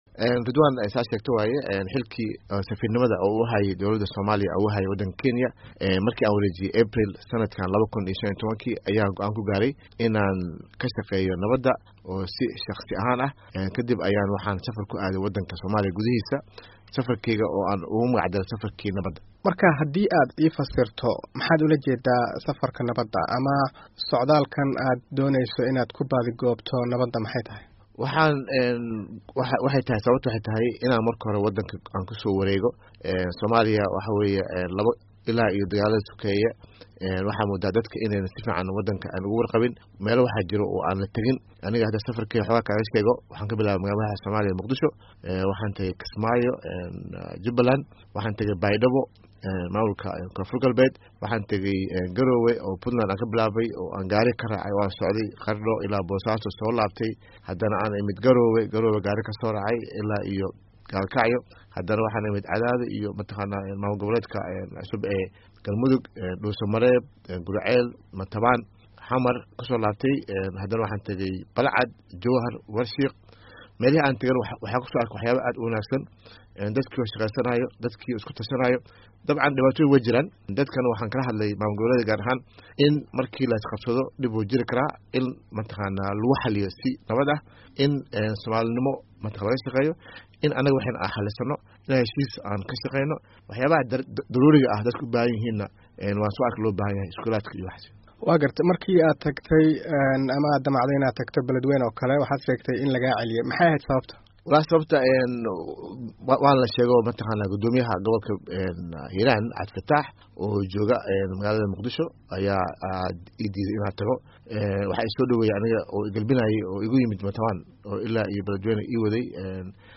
Wareysi: Maxamed Cali America